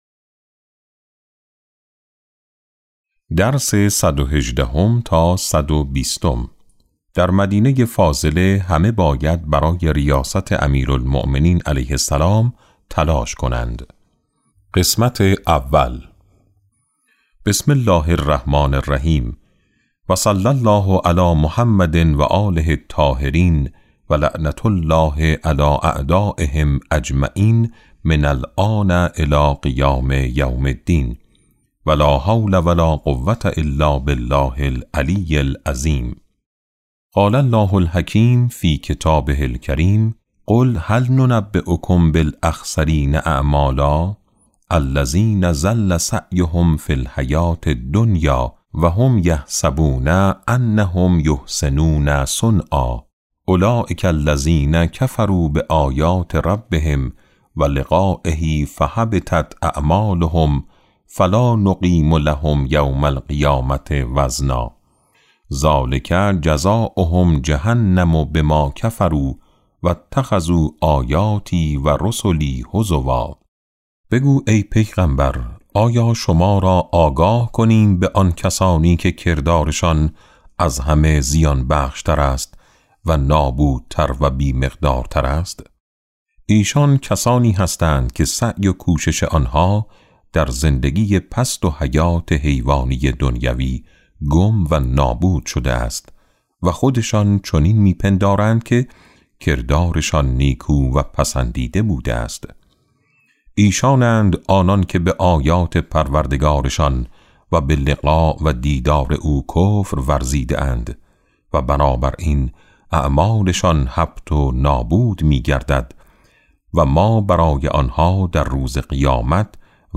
کتاب صوتی امام شناسی ج۸ - جلسه11